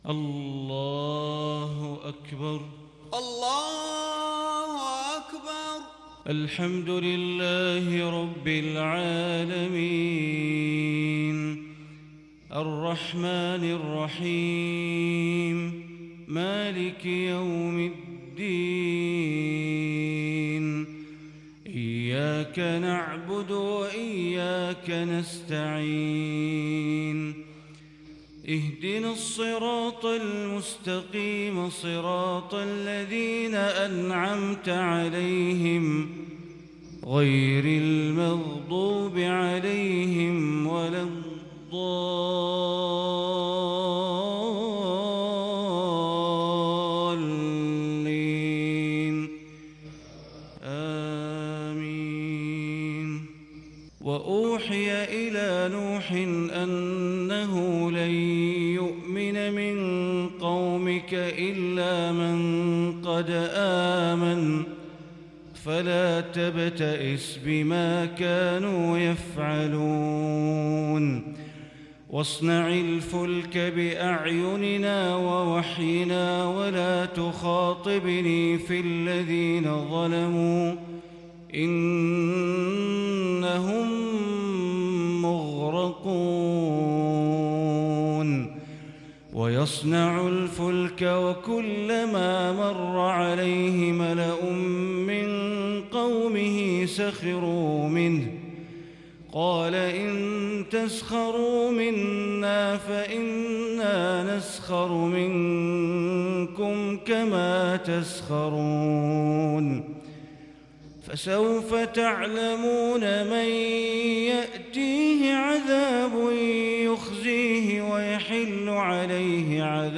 صلاة الفجر للشيخ بندر بليلة 4 ربيع الآخر 1442 هـ
تِلَاوَات الْحَرَمَيْن .